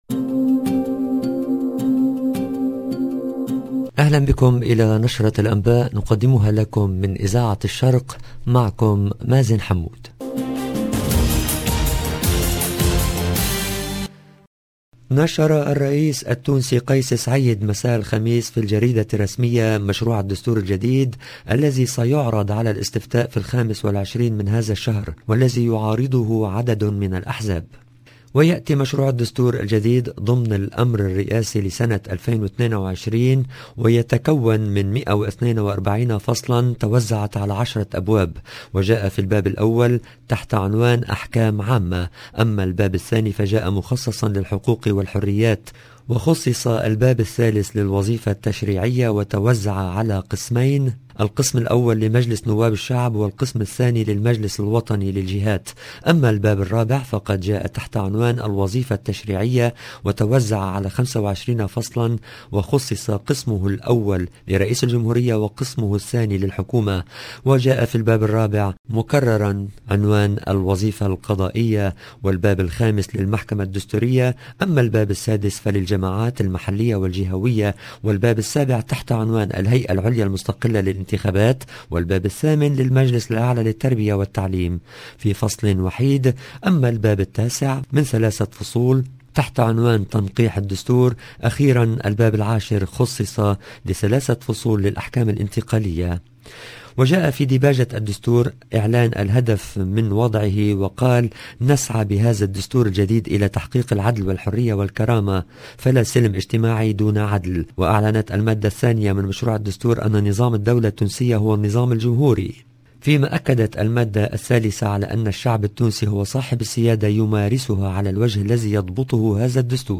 LE JOURNAL EN LANGUE ARABE DU SOIR DU 1/07/22